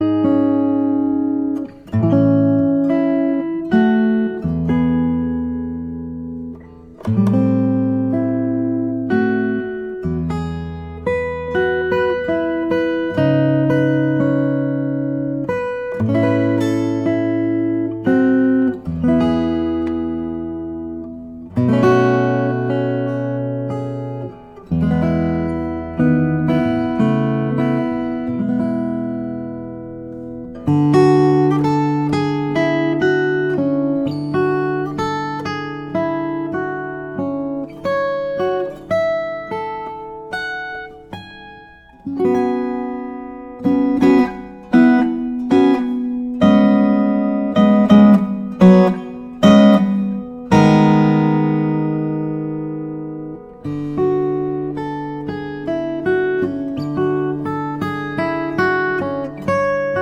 • Sachgebiet: Liedermacher